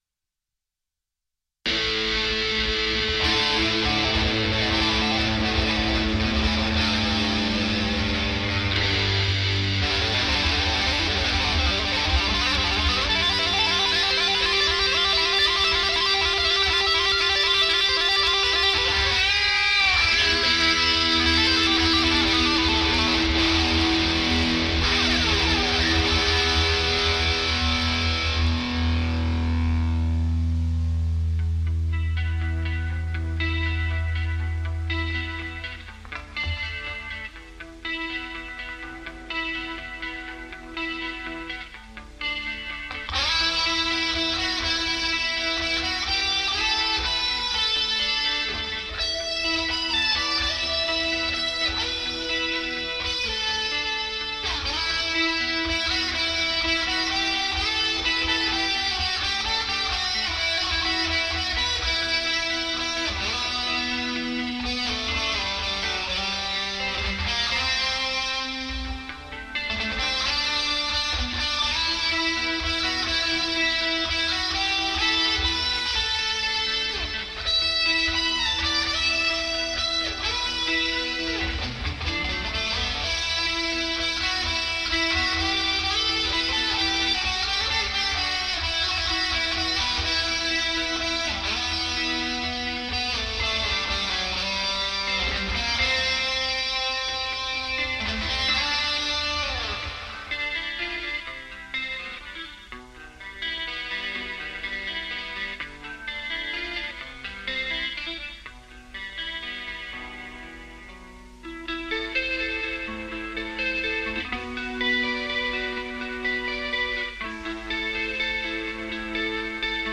Žánr: Metal/HC
kytary, zpěv
bicí
Nahráno: 25.4.1993 v Pub rock clubu (DMD) v Litvínově